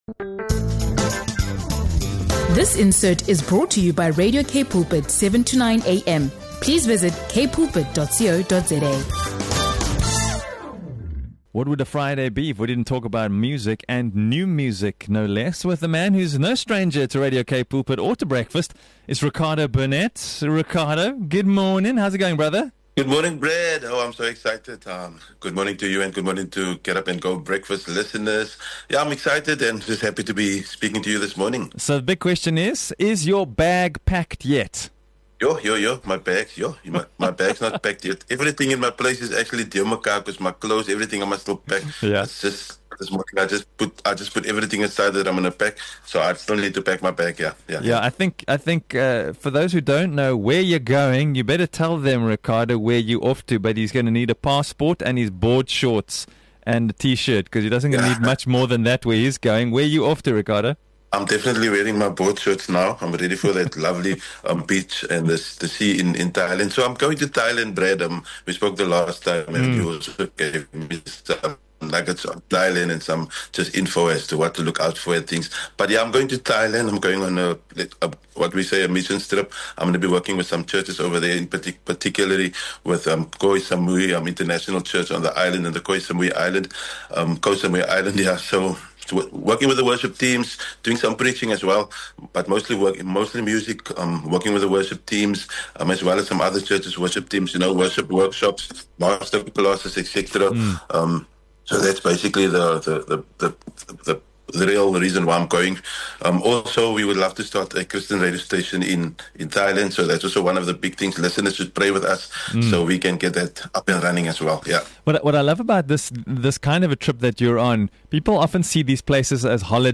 heartfelt conversation